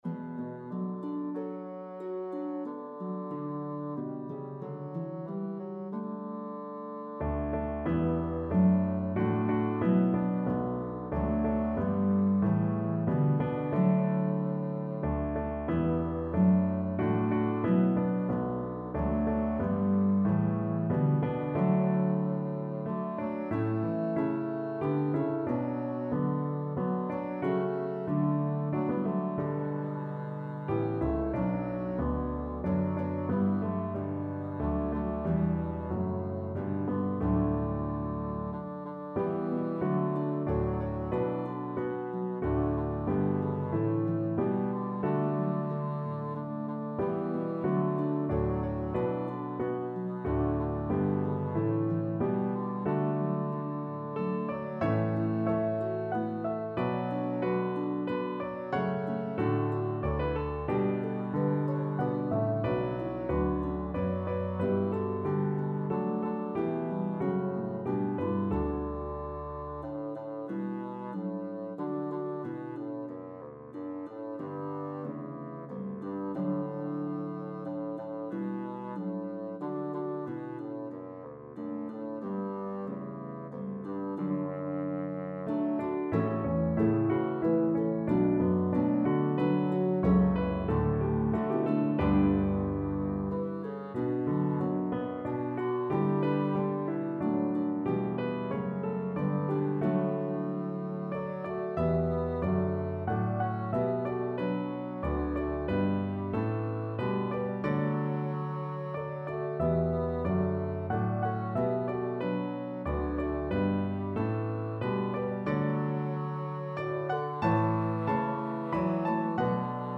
A meditative trio arrangement
pentatonic hymn tune